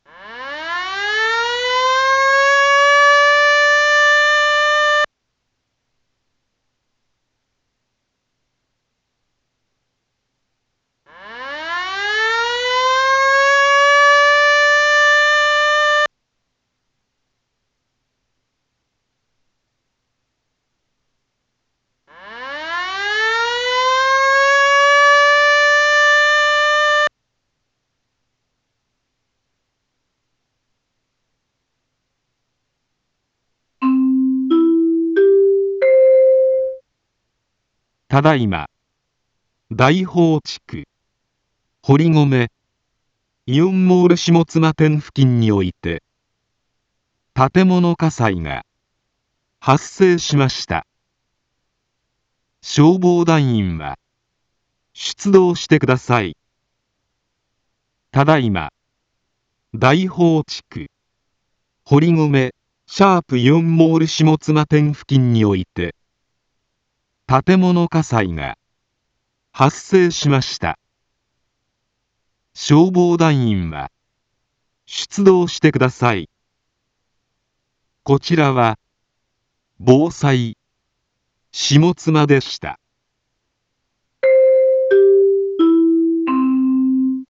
一般放送情報
Back Home 一般放送情報 音声放送 再生 一般放送情報 登録日時：2024-05-16 10:46:31 タイトル：火災報 インフォメーション：ただいま、大宝地区、堀篭、イオンモール下妻店付近において、 建物火災が、発生しました。